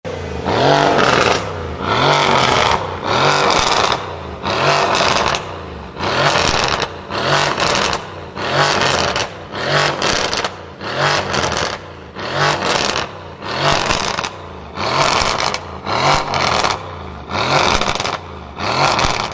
Звуки выхлопа машин
На этой странице собрана коллекция мощных и чистых звуков выхлопа различных автомобилей и мотоциклов.